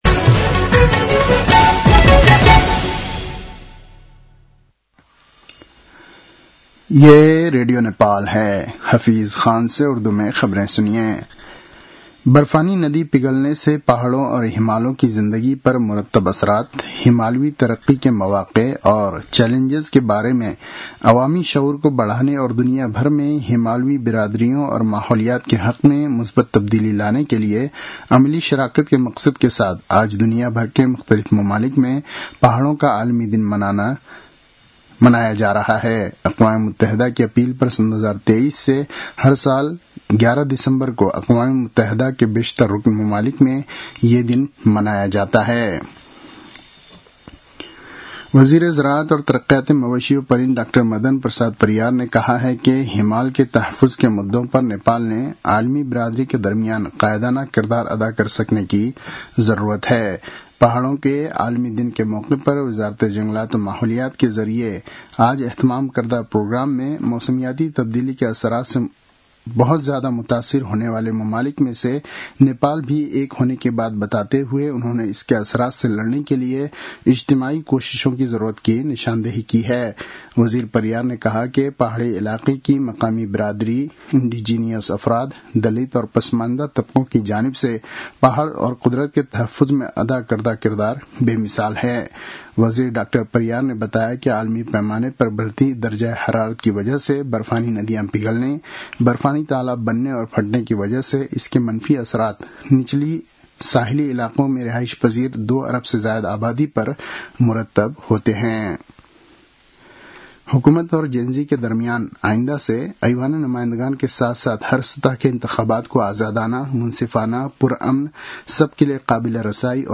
उर्दु भाषामा समाचार : २५ मंसिर , २०८२